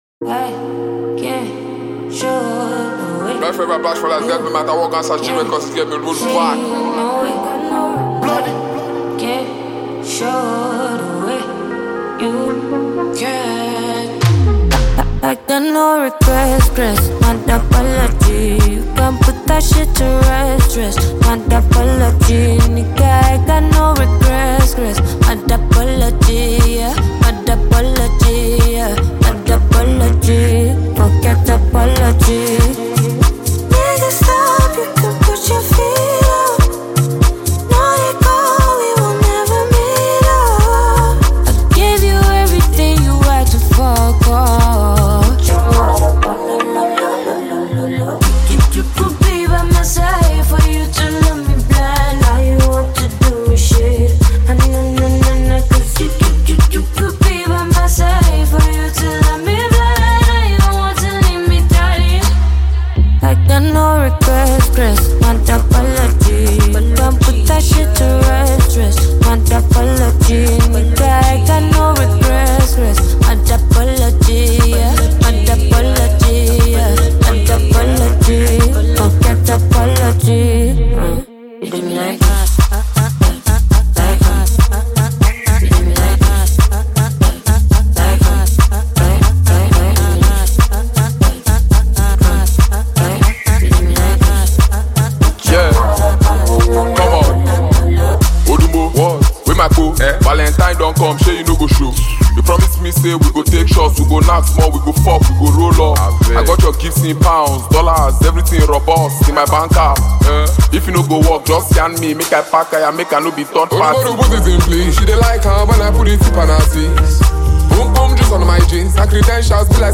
new energizing song